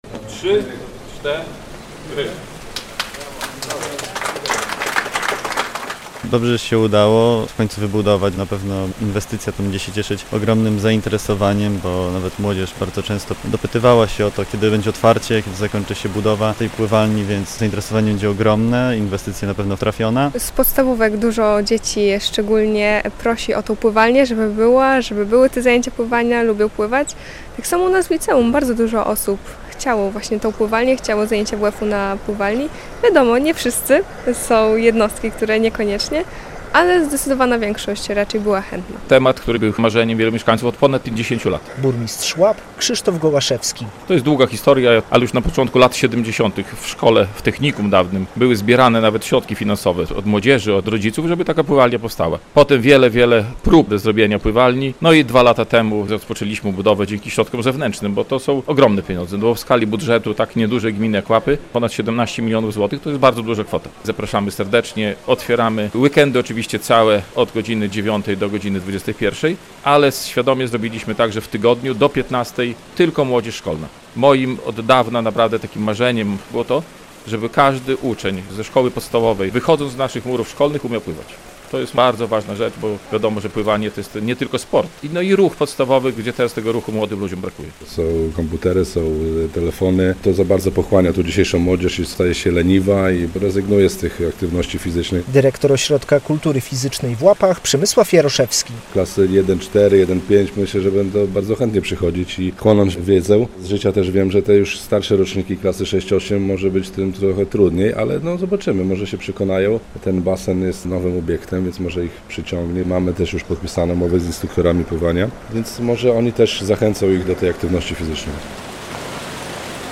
Mieszkańcy Łap mogą już korzystać z krytego basenu - relacja